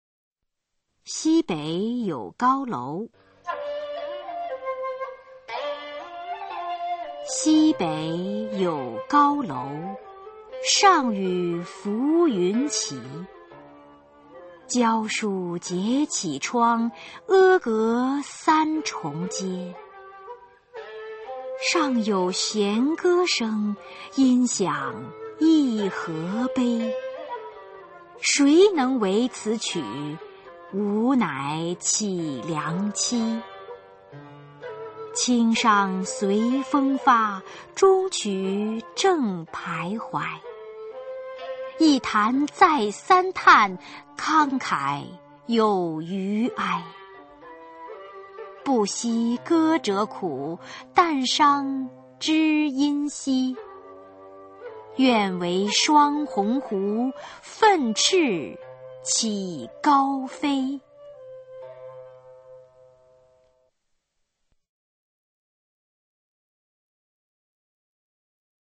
[先秦诗词诵读]古诗十九首-西北有高楼 朗诵